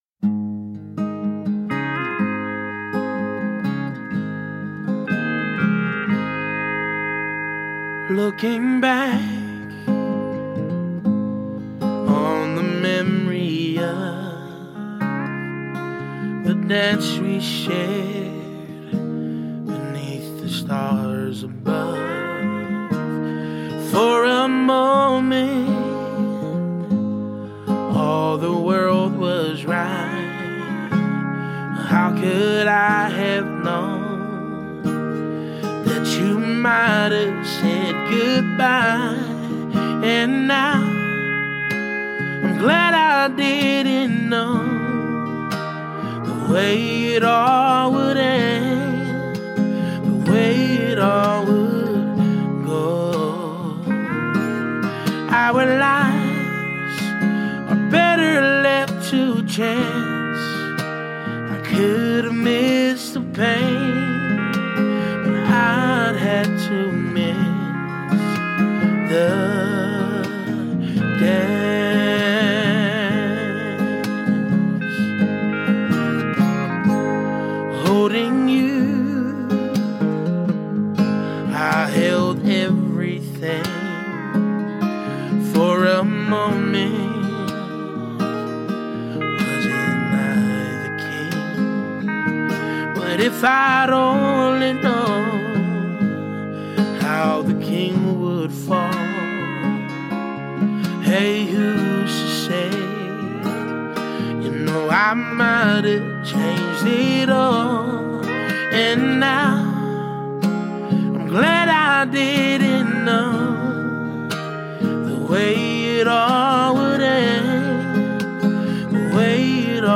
Wir hören hinein und sprechen mit Verantwortlichen.